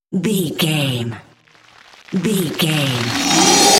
Creature dramatic riser
Sound Effects
In-crescendo
Atonal
scary
ominous
haunting
eerie
roar